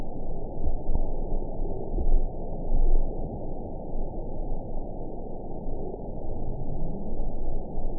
event 920704 date 04/04/24 time 16:34:29 GMT (1 year ago) score 7.99 location TSS-AB07 detected by nrw target species NRW annotations +NRW Spectrogram: Frequency (kHz) vs. Time (s) audio not available .wav